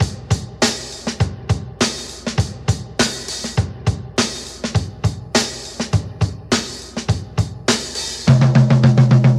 • 102 Bpm '60s Drum Loop Sample G Key.wav
Free drum groove - kick tuned to the G note.